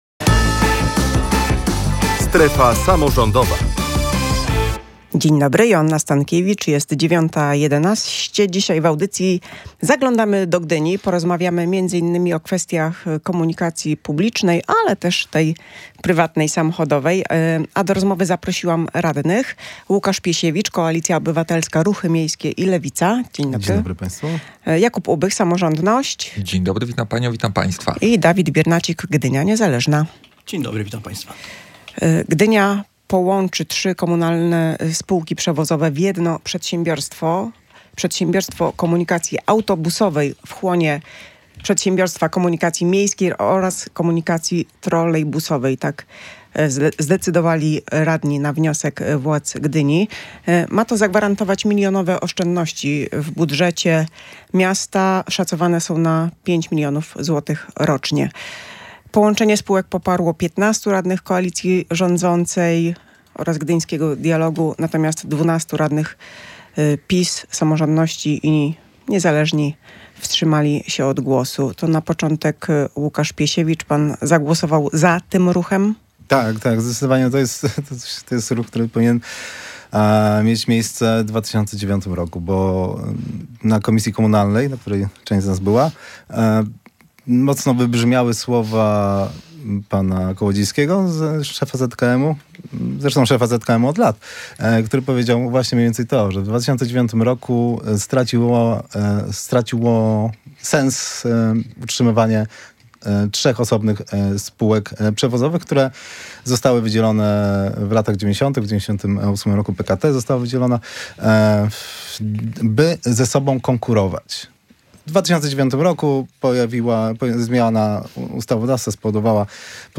Między innymi o tym w „Strefie Samorządowej” rozmawialiśmy z radnymi.